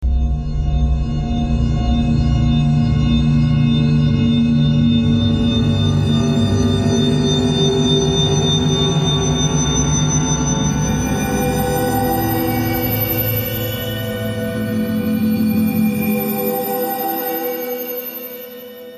背景噪音
描述：背景在演播室里面的沉默记录。
标签： 背景 噪音 气氛
声道立体声